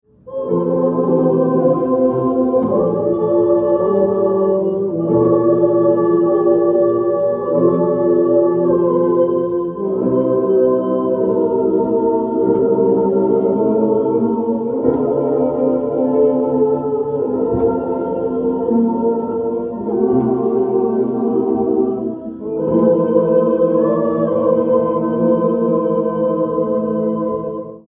corrige realisation a 4 voix 19.mp3